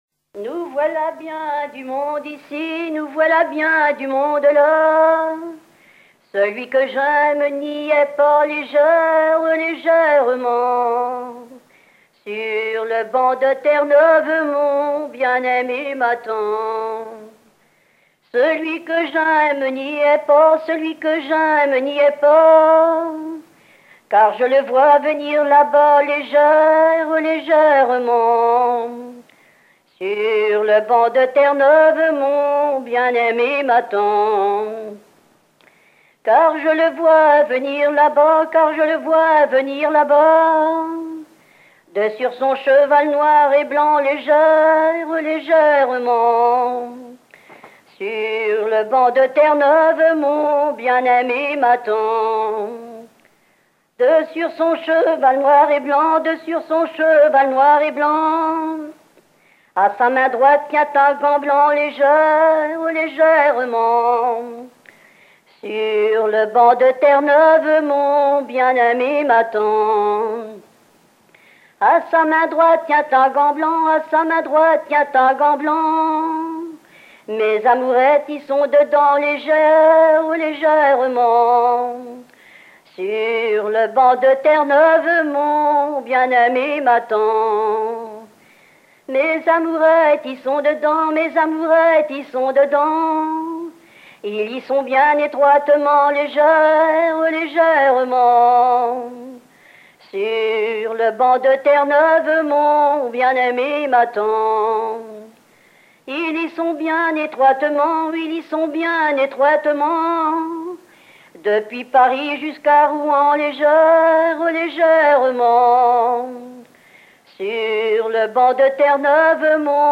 Enregistré en 1960
danse : ronde à trois pas
Genre laisse
Pièce musicale éditée